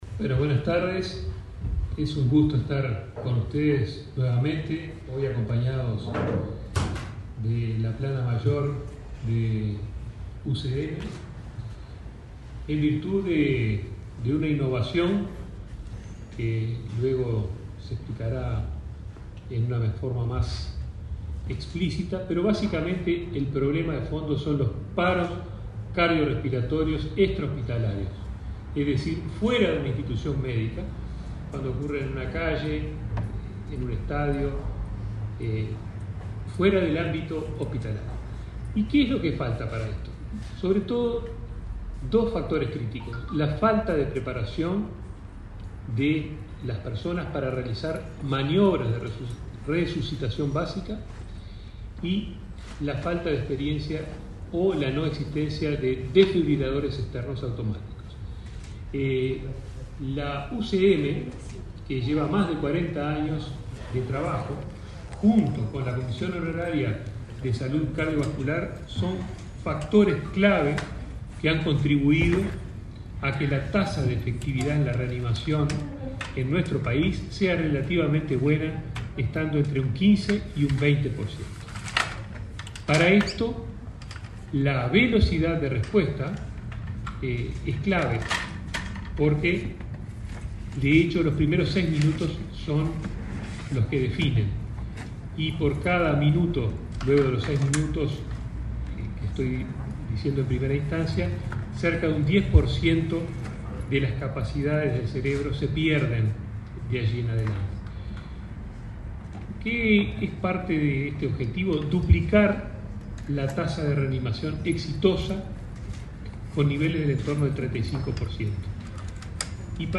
Conferencia de prensa por presentación del programa El Valor de Una Vida
Participaron del evento el ministro de Salud Pública, Daniel Salinas; el subsecretario, José Luis Satdjian